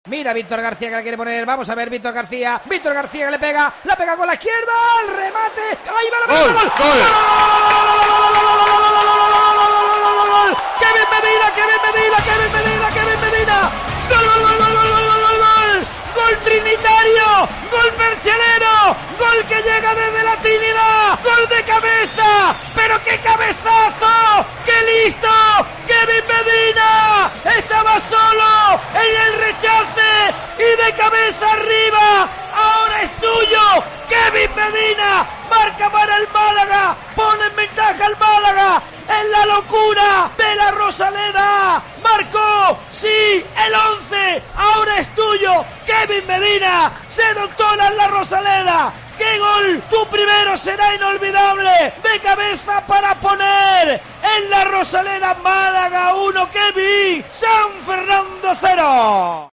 Así sonó en COPE Málaga el primer gol de Kevin con el Málaga
Así contamos el primer gol de Kevin Medina con el Málaga